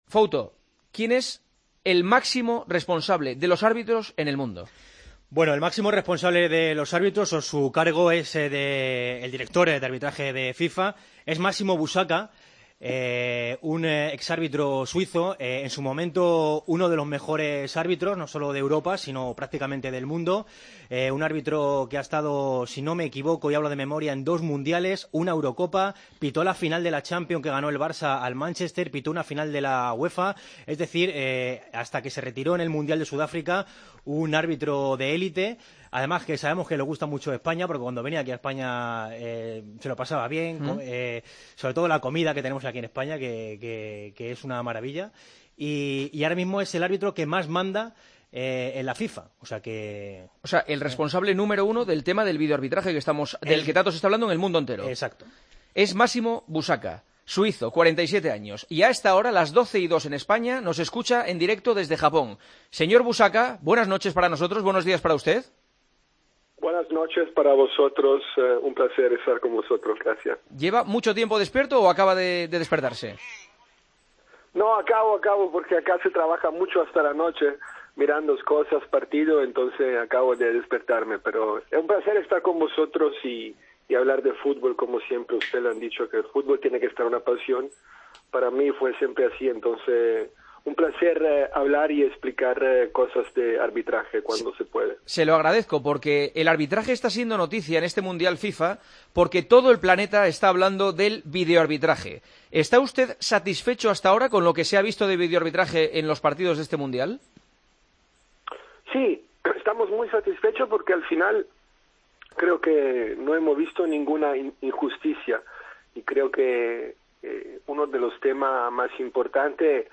El jefe del comité de árbitros de la FIFA, Massimo Busacca, aclaró en El Partidazo de COPE en qué consiste el uso de la tecnología y, en concreto, del polémico videoarbitraje: